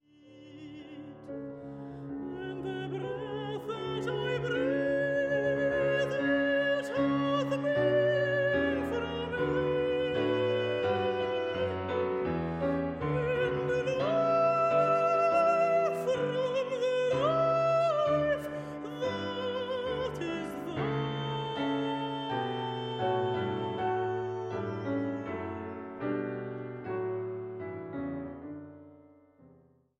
Contratenor
Piano